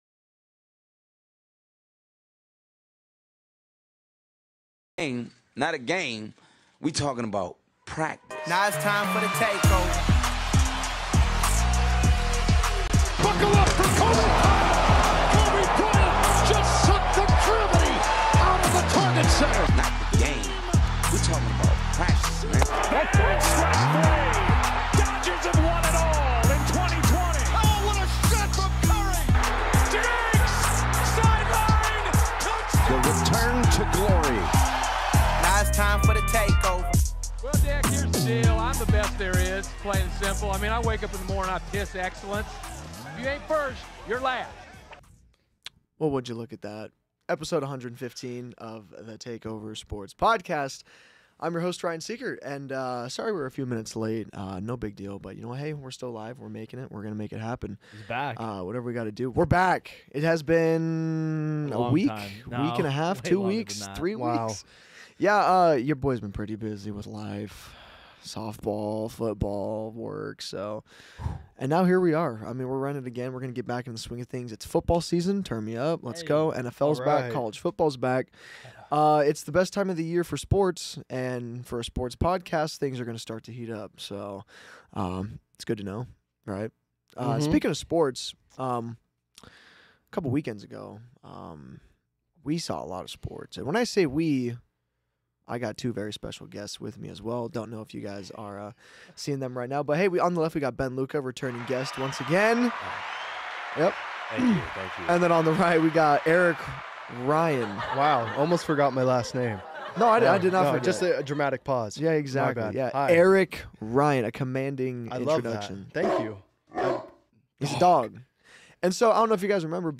- Aired Live 09/20/2022